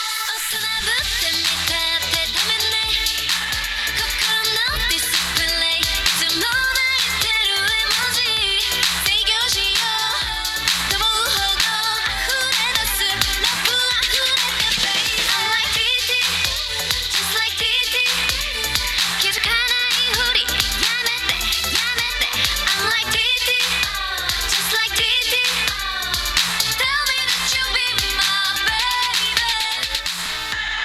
以下は前回の記事でも紹介しているエージング前のハイレゾ録音です。